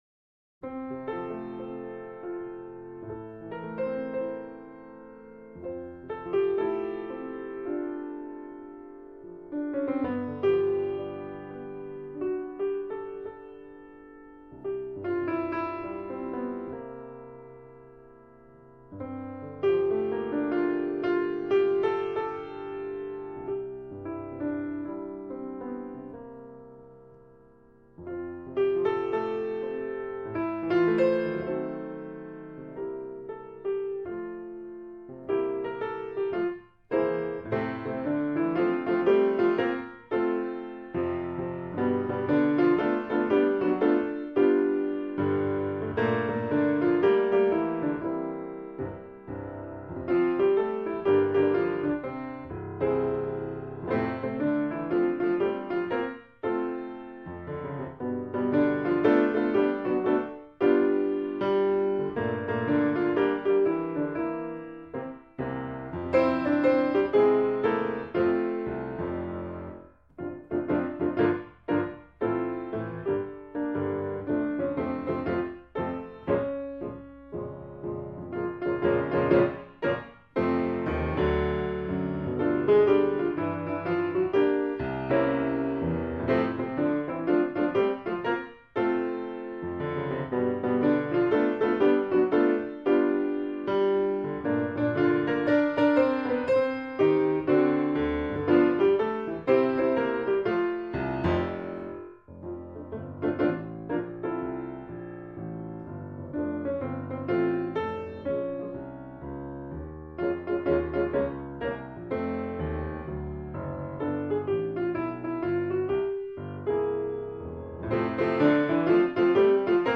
เปียโน